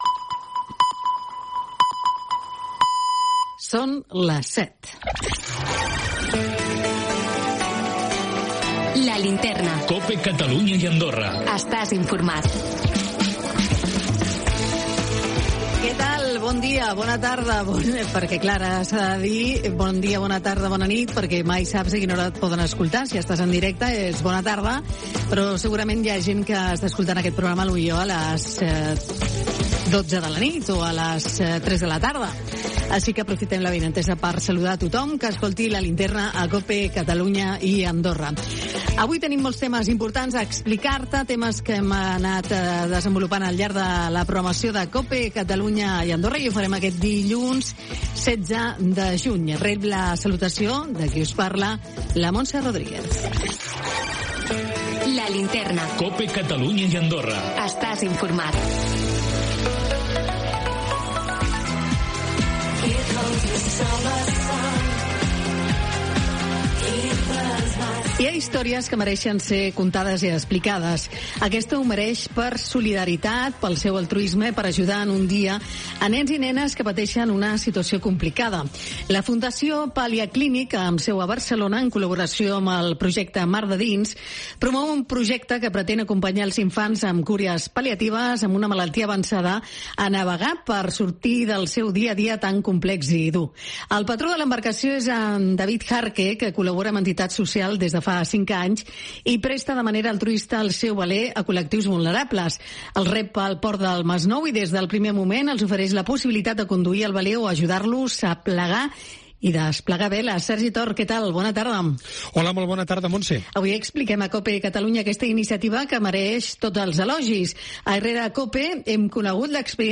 Audio-entrevista-a-La-Linterna-COPE-CATALUNA-a-Fundacion-Paliaclinic-16-6-25_C.mp3